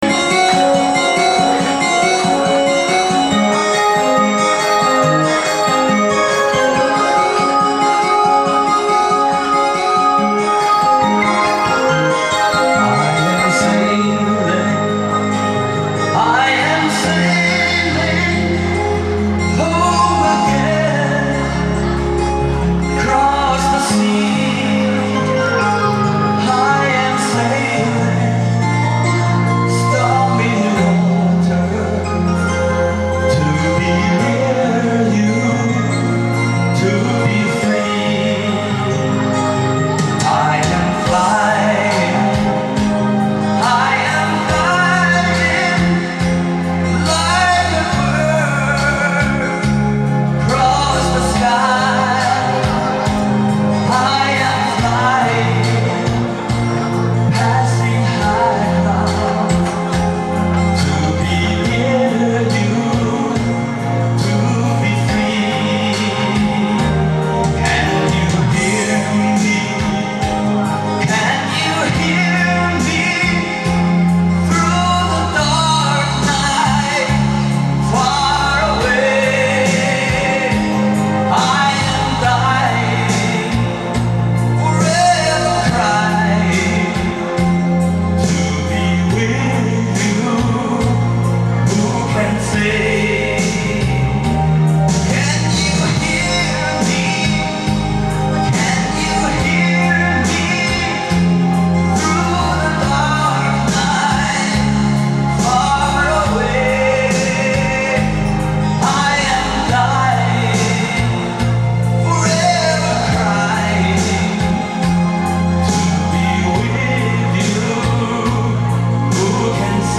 本人翻唱(刚录)---无伴奏Danny Boy(丹尼男孩) 激动社区，陪你一起慢慢变老！